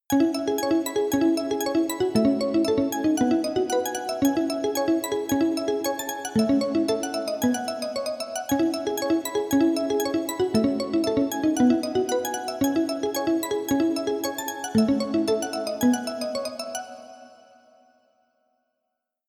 5) Маримба
5) Встроенный в FL синт Sytrus с большим кол-вом конволюционного хола.
marimba.mp3